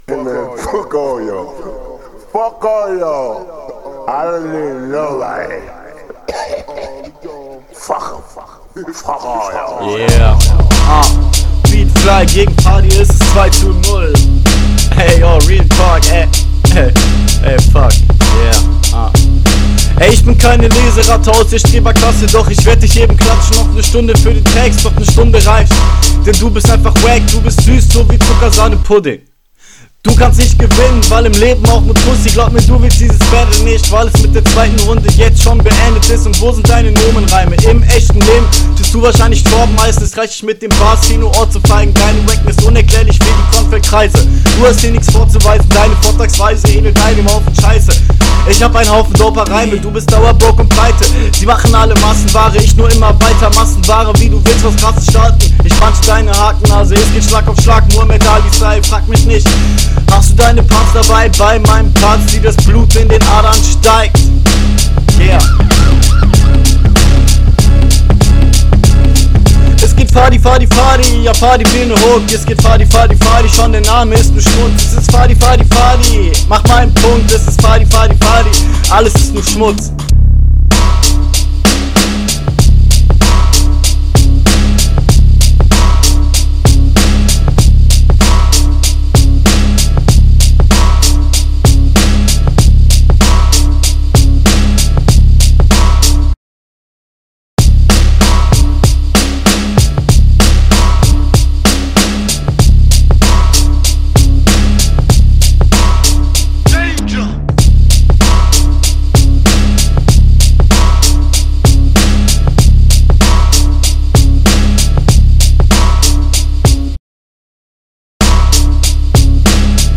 Flow wirkt unroutiniert und zieht defizite durch die ganze Ästethik, dennoch fand ich manche Lines …
Uh oldschool Beat. Erstmal zur Aufnahmequalität: Dein Mic übersteuert etwas und es klingt allgemein so, …